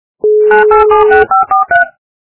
При прослушивании Набор - номера качество понижено и присутствуют гудки.
Звук Набор - номера